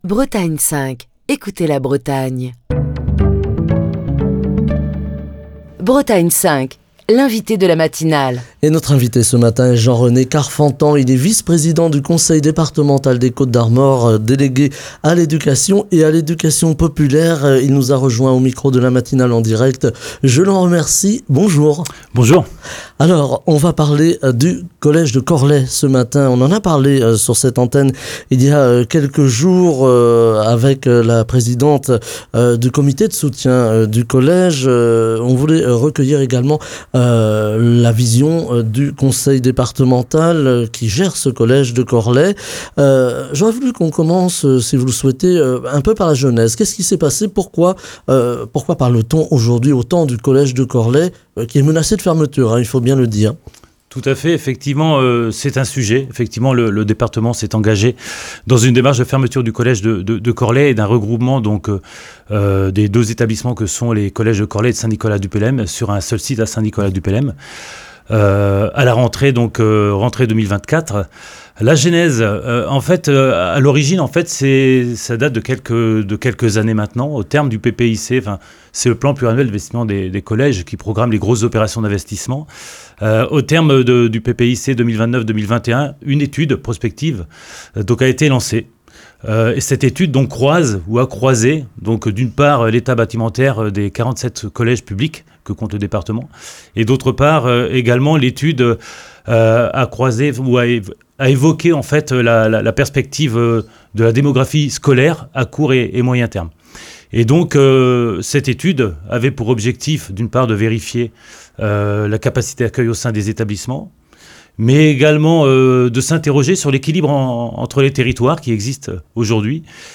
Ce matin, nous revenons sur la fermeture du collège Pier-An-Dall de Corlay (22) avec Jean-René Carfantan, vice-président du Conseil Départemental des Côtes d'Armor, délégué à l’Éducation et à l’Éducation populaire, invité de la matinale de Bretagne 5.